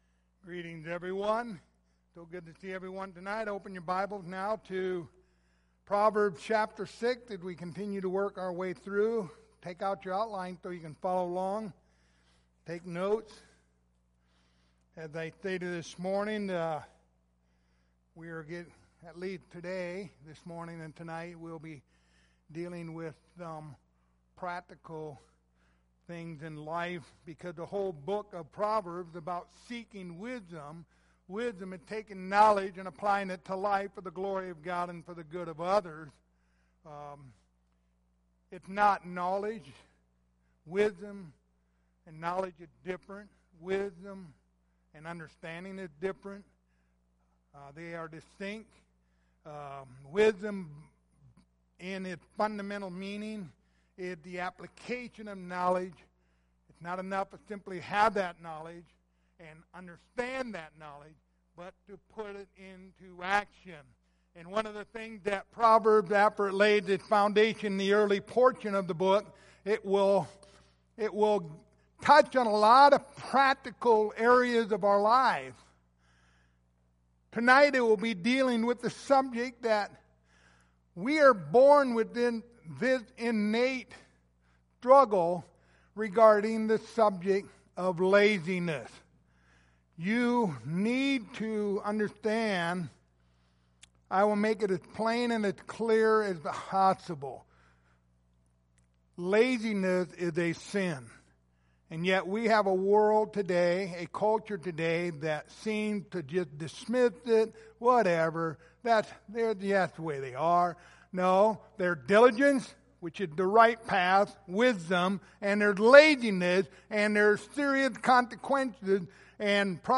Passage: Proverbs 6:6-11 Service Type: Sunday Evening